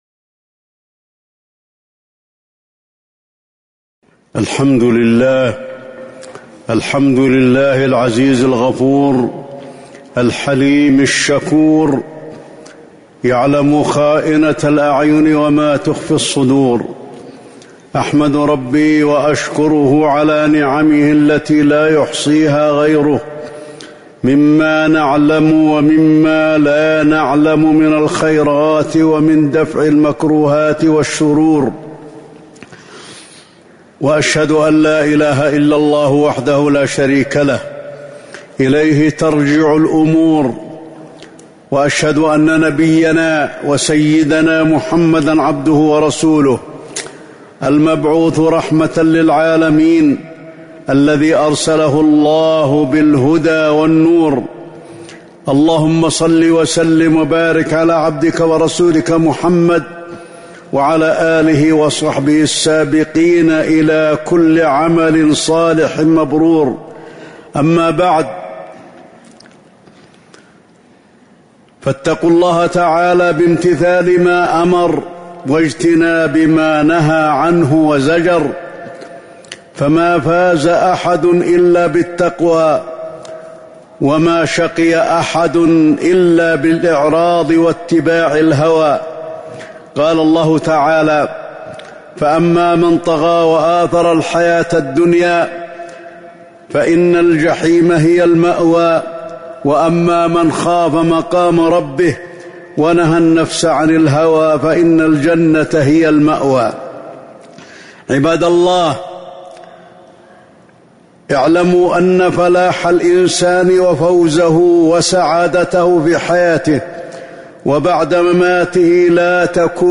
تاريخ النشر ١٩ شوال ١٤٤٣ هـ المكان: المسجد النبوي الشيخ: فضيلة الشيخ د. علي بن عبدالرحمن الحذيفي فضيلة الشيخ د. علي بن عبدالرحمن الحذيفي الثبات على طاعة الله تعالى